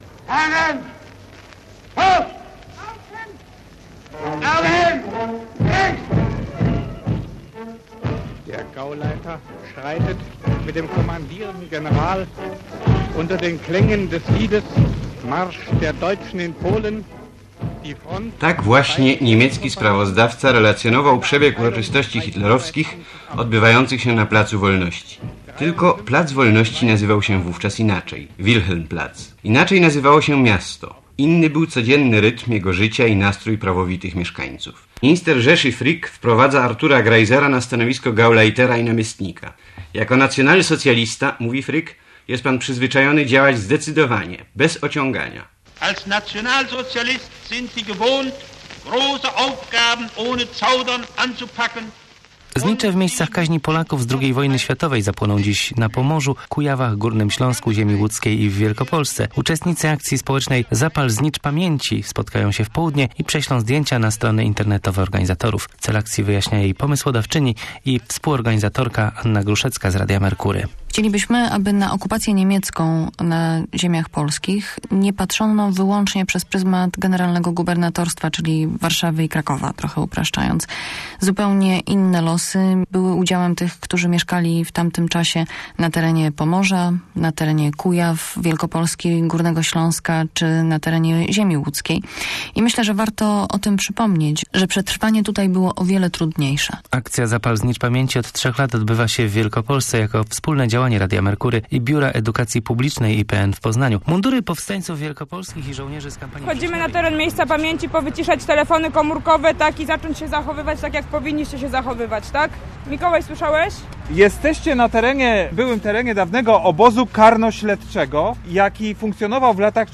Znicz Pamięci - reportaż